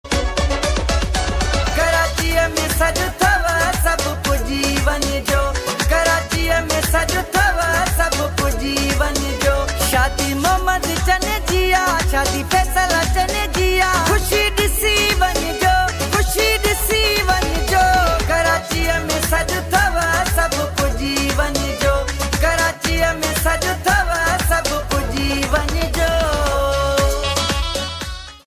Sindhi Mashup Song